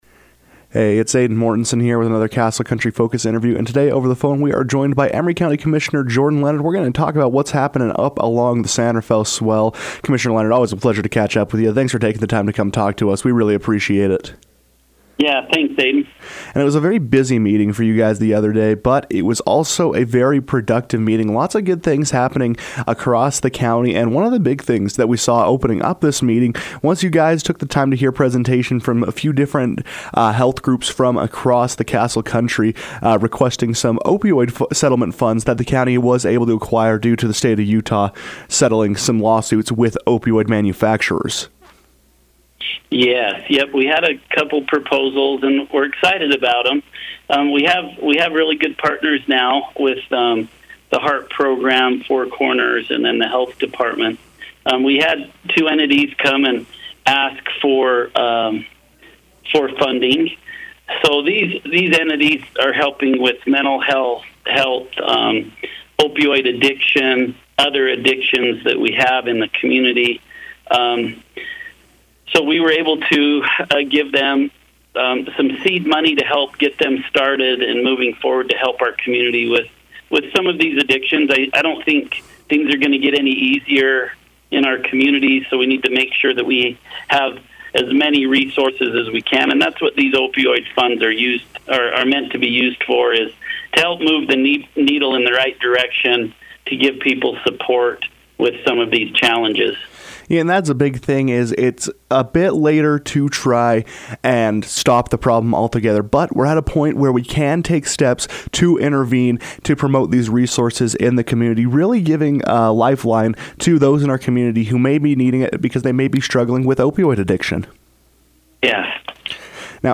Emery County Commissioner Jordan Leonard joined the KOAL newsroom to discuss citizen concerns across the county and the distribution of opioid settlement funds to programs in the area.